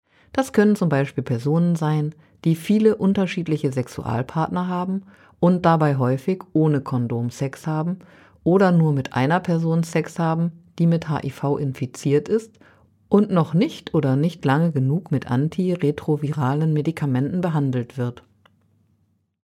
O-Töne14.12.2023